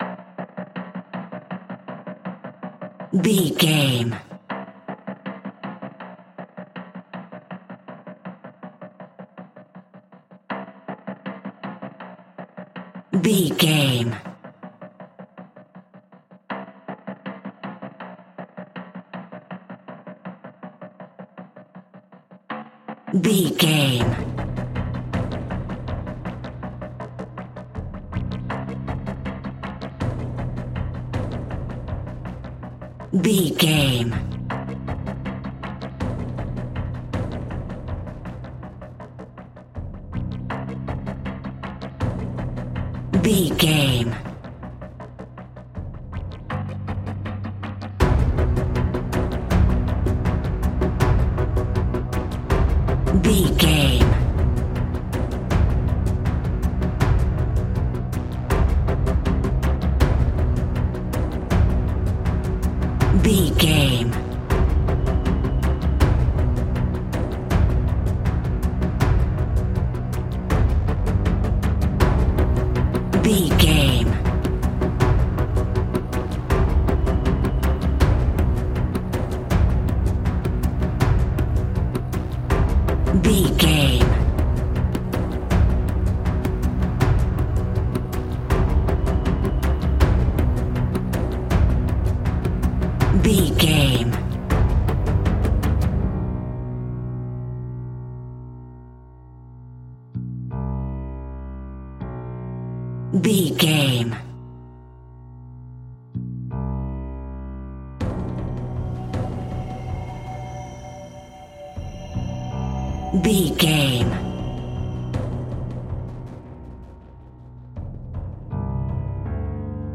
Aeolian/Minor
scary
ominous
dark
haunting
eerie
drums
synthesiser
piano
strings
horror music
Horror Pads